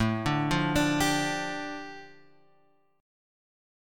A7sus4#5 chord {5 5 5 7 6 5} chord